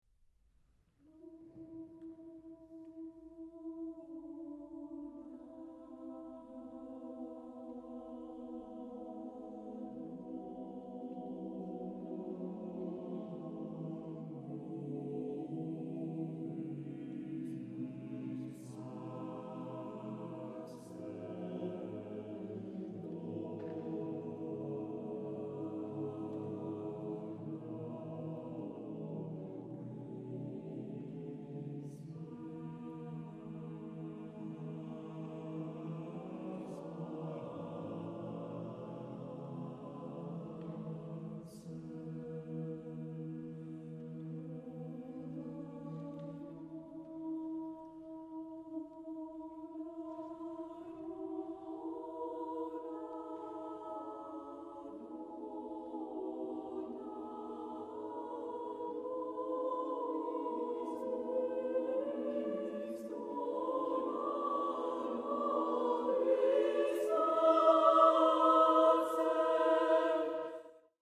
Genre-Style-Forme : Sacré ; Motet
Caractère de la pièce : suppliant
Type de choeur : SSATBB  (6 voix mixtes )